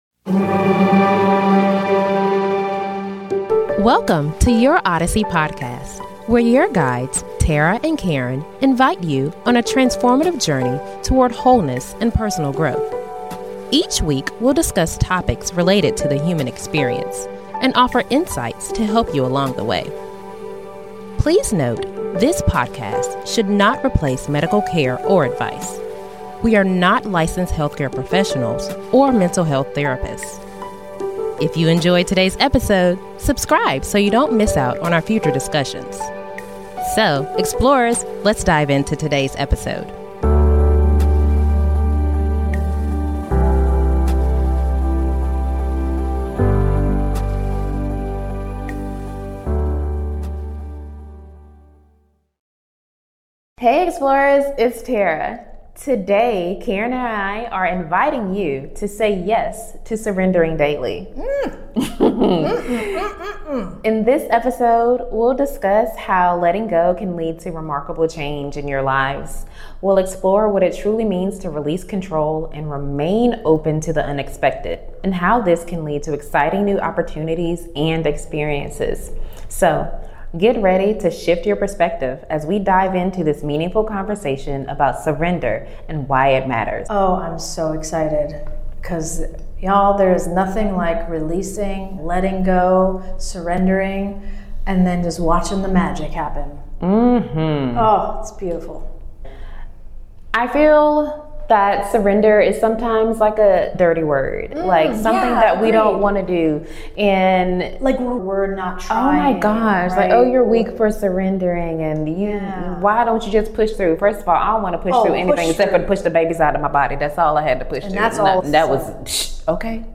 Tune in for a heartfelt conversation filled with inspiration and positivity.